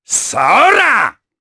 Baudouin-Vox_Attack3_jp.wav